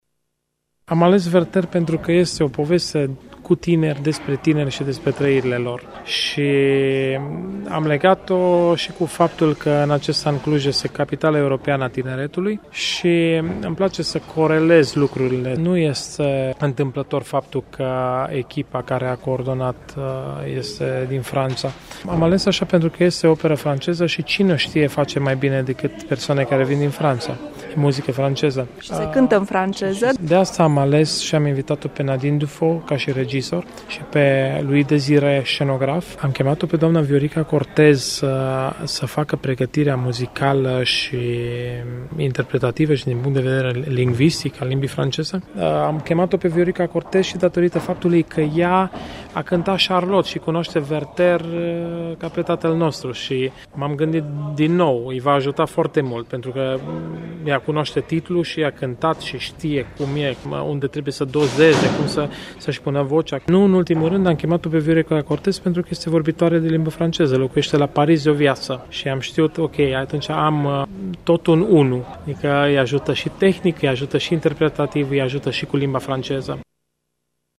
Promo-pt-10-oct-Werther.mp3